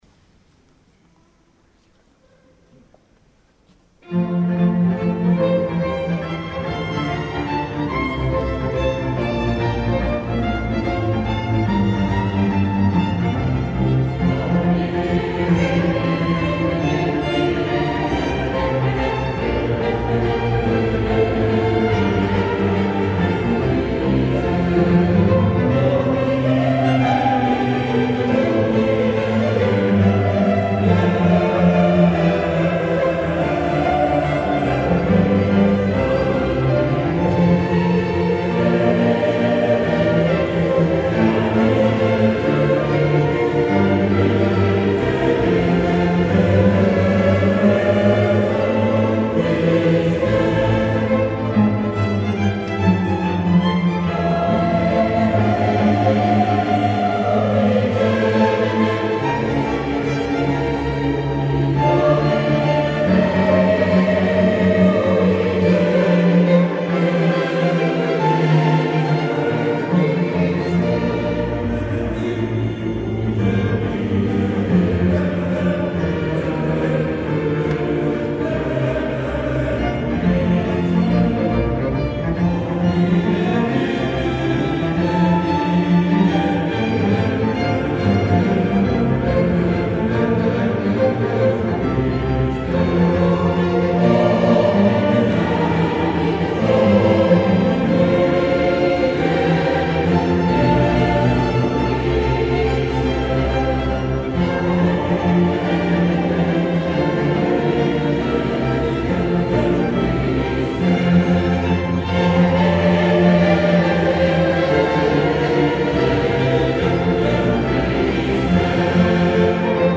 Abbiategrasso  Basilica di Santa Maria Nuova
Concerto benefico per gli orfani dell'AIDS in Tanzania
Con la Camerata musicale "Luigi Mori" Città di Castello
brani in formato audio MP3 del concerto